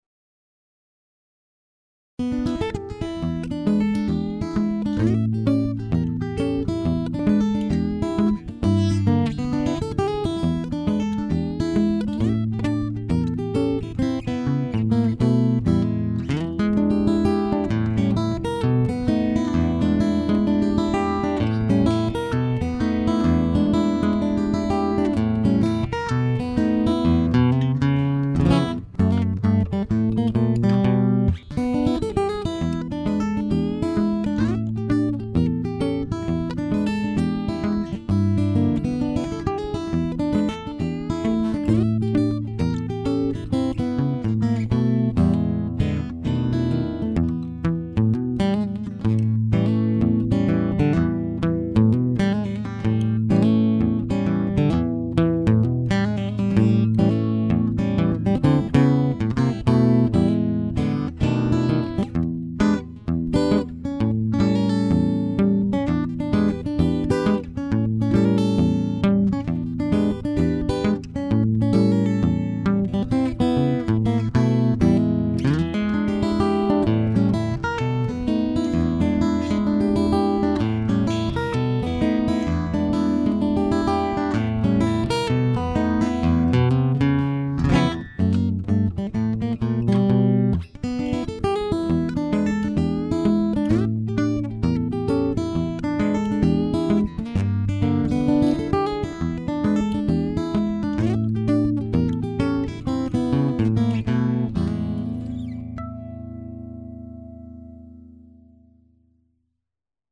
This guy is a very good guitarist and has recorded some songs with this guitar.
The sound of this Adamas is purely amazing!!
The Adamas sounds awful sweet too!